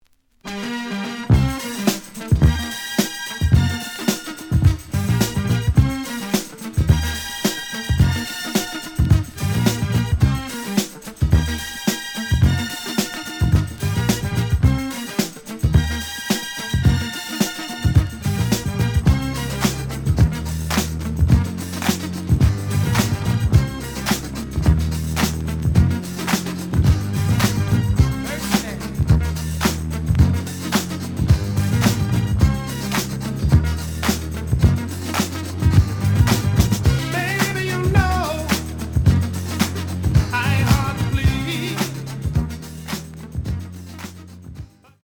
The audio sample is recorded from the actual item.
●Genre: Disco
Slight edge warp. But doesn't affect playing. Plays good.